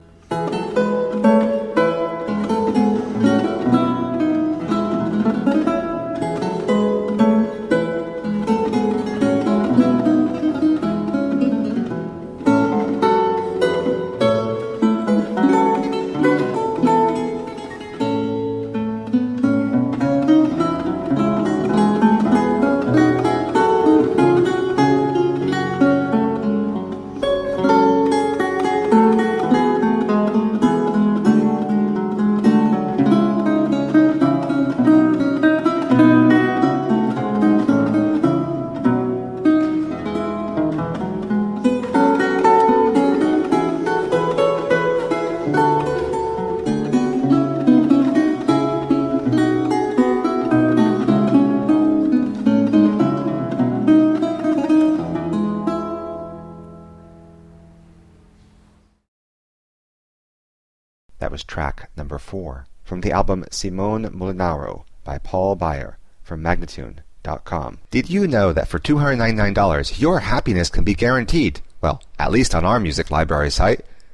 Solo lute of the italian renaissance..